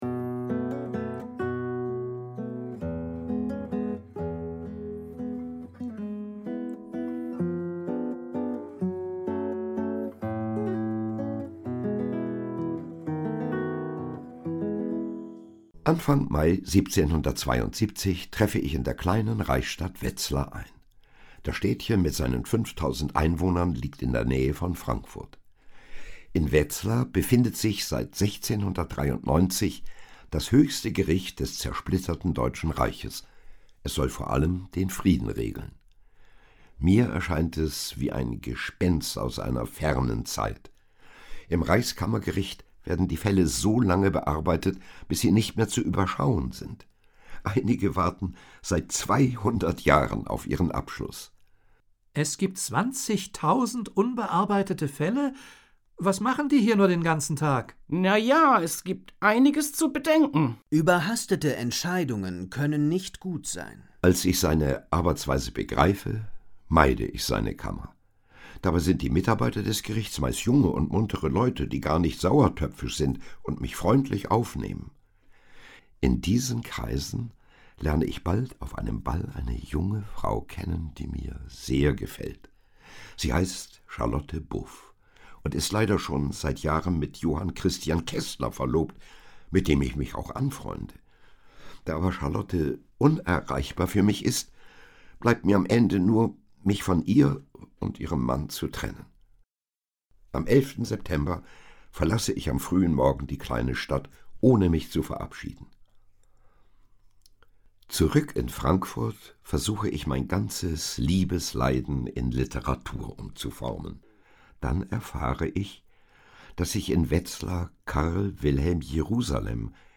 Hörbuch
Seine stimmliche Präsenz und enorme Wandlungsfähigkeit bestechen in jedem Genre vom Gedicht bis zur Prosa, vom gespielten Dialog bis zum informativen Sachtext.